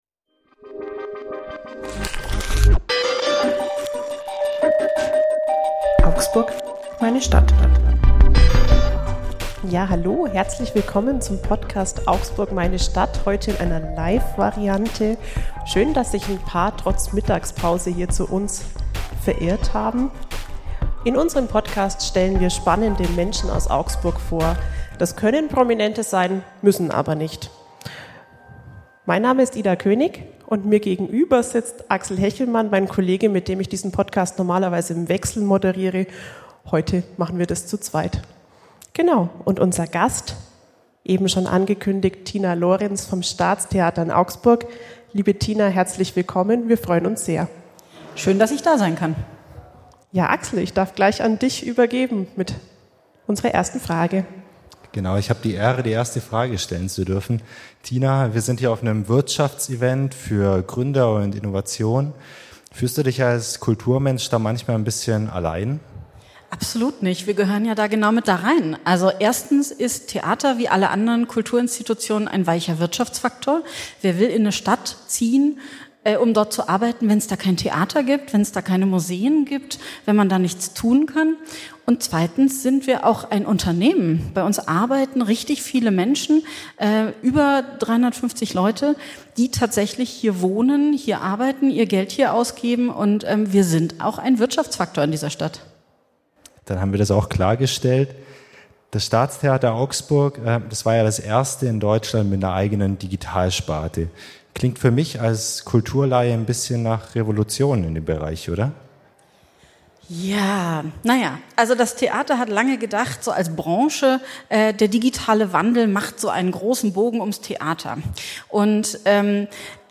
(Live-Podcast) ~ Augsburg, meine Stadt Podcast